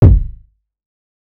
TC3Kick1.wav